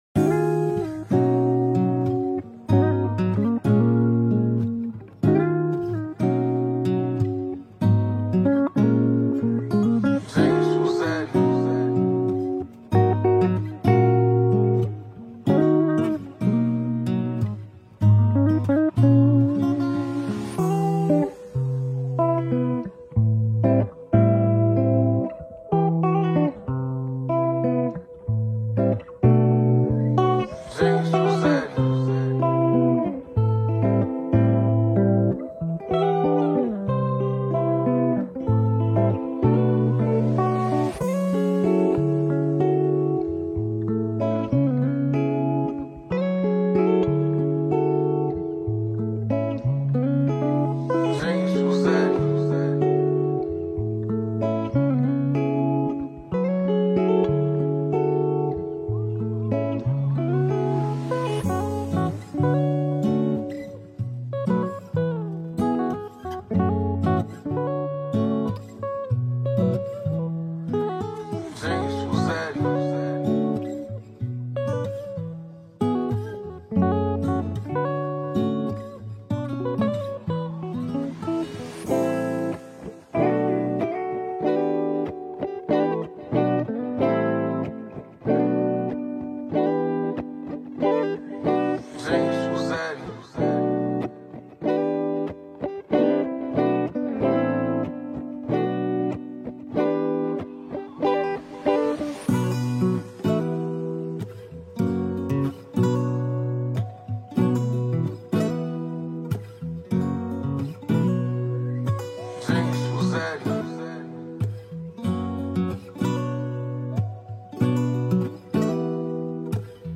West Coast, LA signature sound